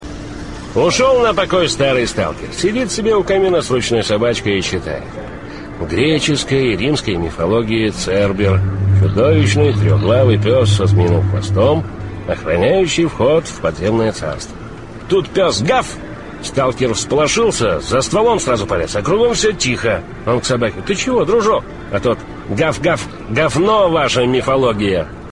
Фрагмент звукового оформления компьютерной игры "S.T.A.L.K.E.R.".
Профессиональный анекдот сталкеров на мифологическую тематику, рассказанный у костра.